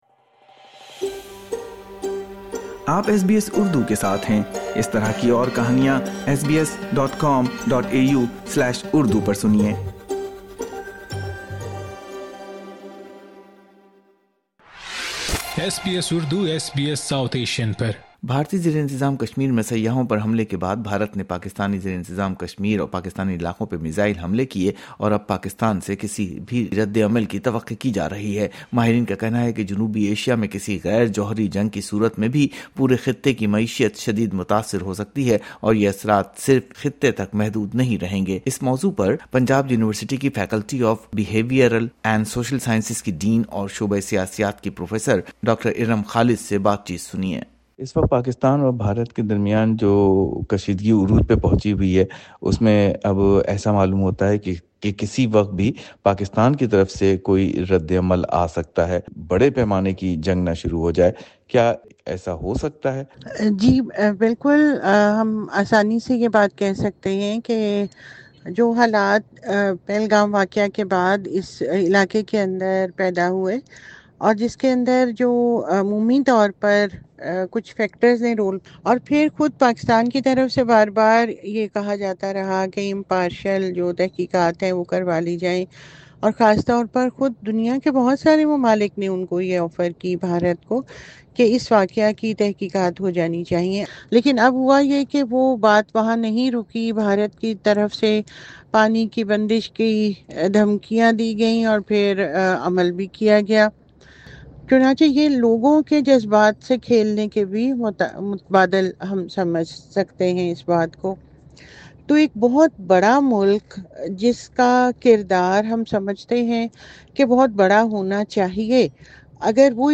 جنوبی ایشیا میں کسی جنگ کا خطے پر کیا اثر پڑ سکتا ہے؟ اس موضوع پر تجزیاتی بات چیت سنئے